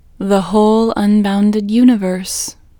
WHOLENESS English Female 14